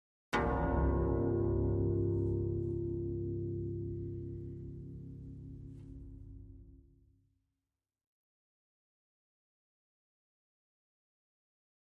Harp, Deep Single Tone, Type 2